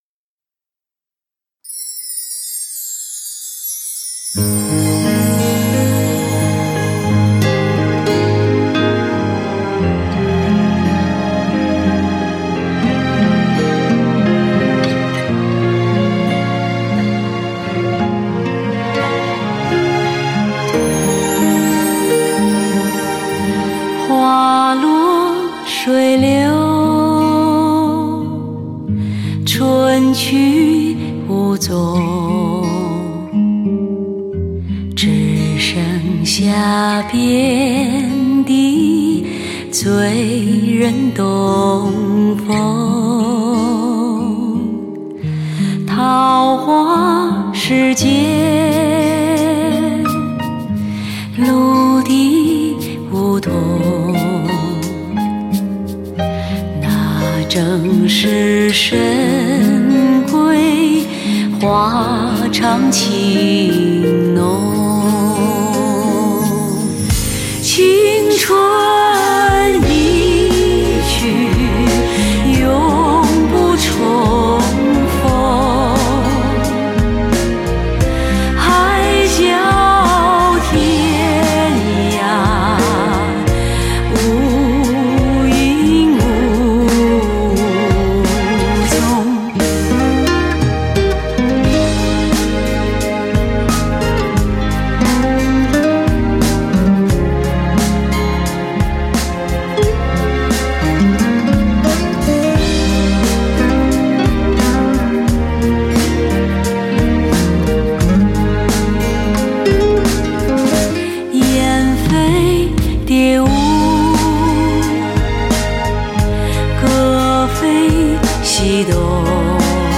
高临场感CD